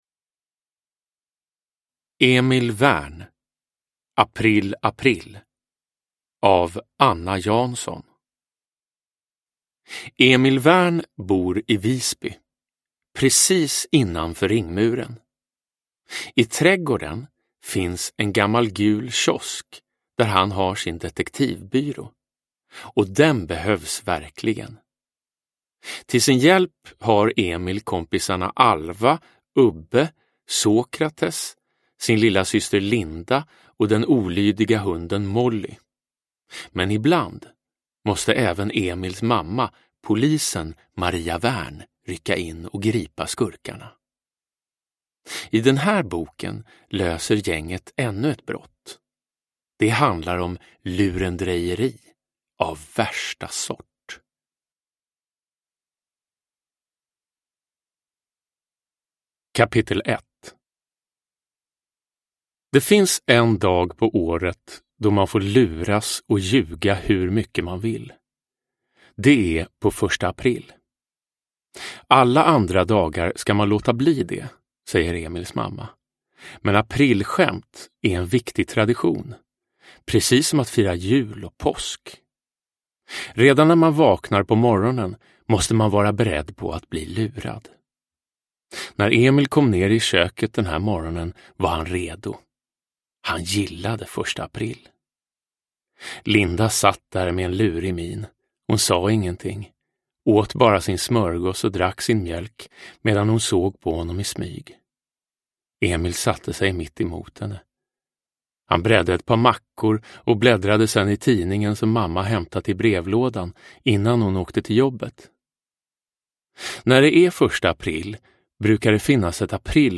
April, april är ett helt nytt Emil Wern-mysterium av mästerliga Anna Jansson och kommer först som ljudbok.
Uppläsare: Jonas Karlsson